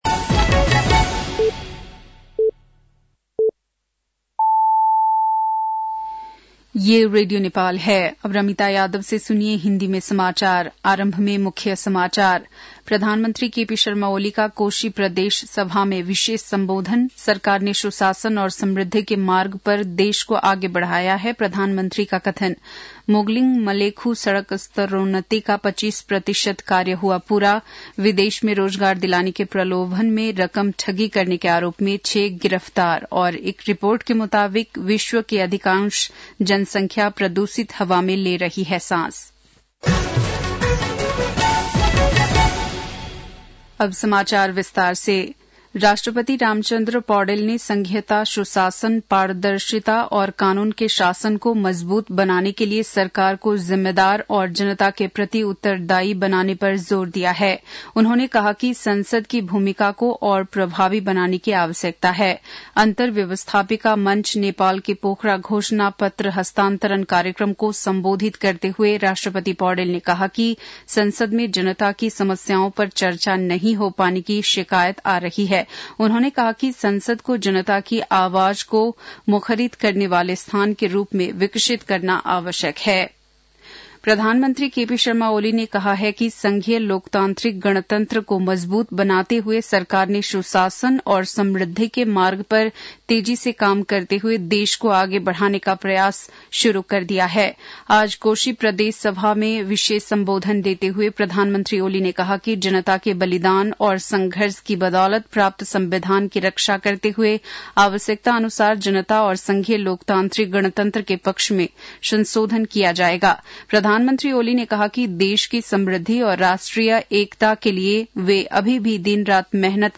बेलुकी १० बजेको हिन्दी समाचार : २८ फागुन , २०८१